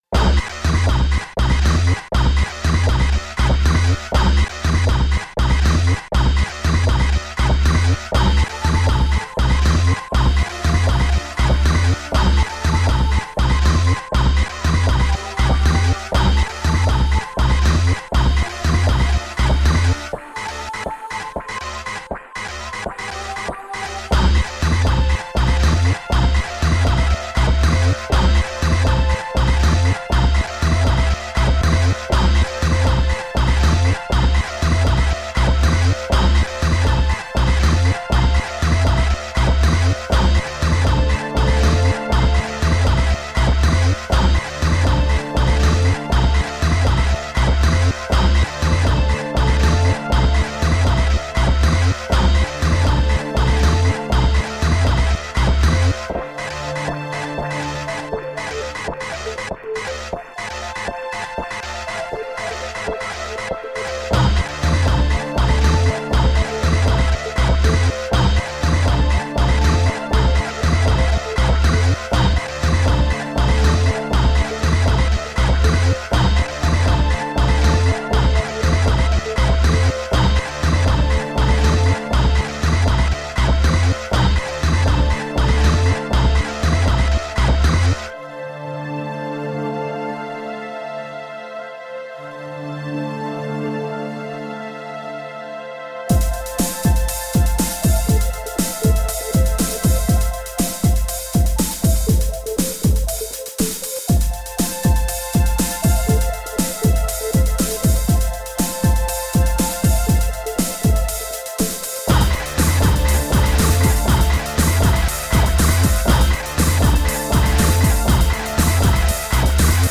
Sublime and ethereal electronic music.
Tagged as: Electronica, Techno